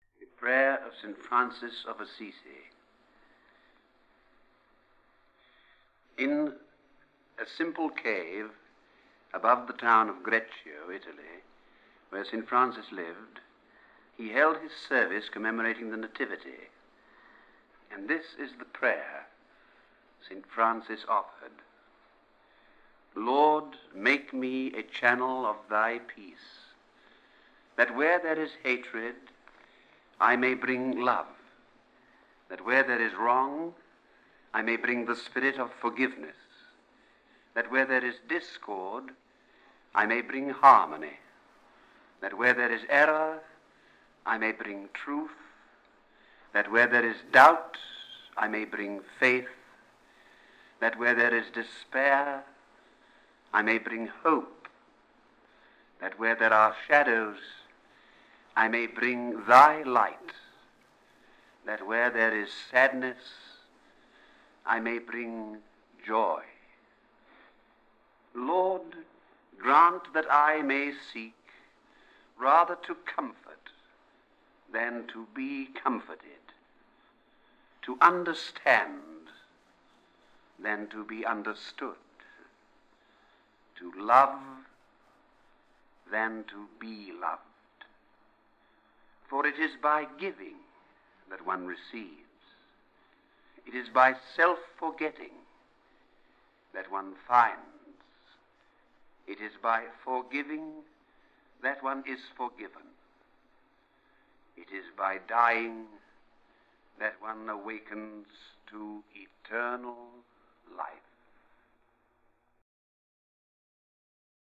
When his health made it difficult for him to pursue acting roles, he began to record favourite poems in his home.